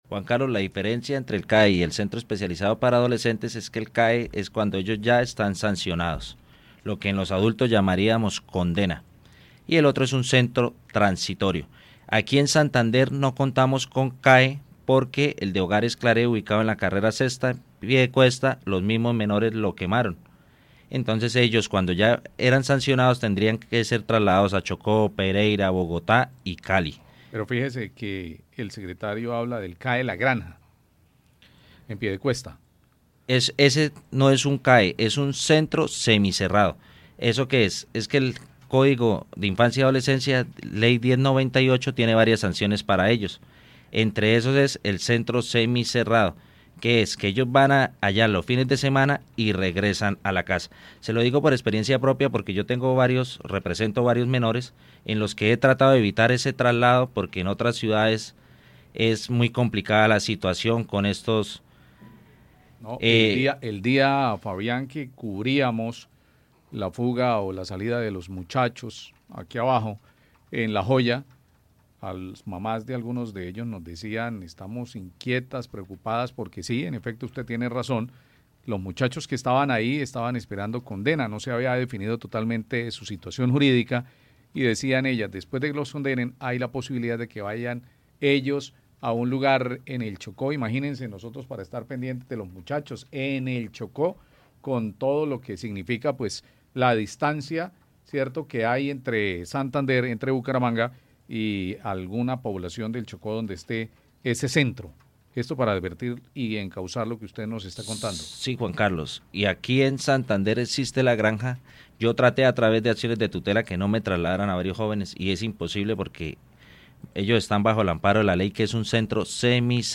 Un abogado experto en el sistema de responsabilidad penal para adolescentes explica las diferencias con los centros de internamiento.